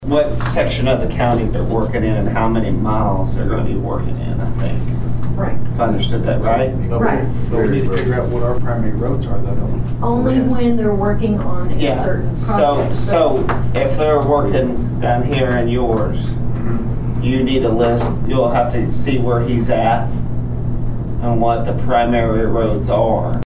The Nowata County Commissioners met for a regularly scheduled meeting Monday morning at the Nowata County Annex.
District Two Commissioner Brandon Wesson and County Clerk Kay Spurgeon discussed the role of a primary road.